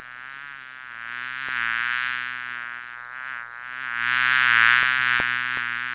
Bee Buzzing Download
Beebuzzing.mp3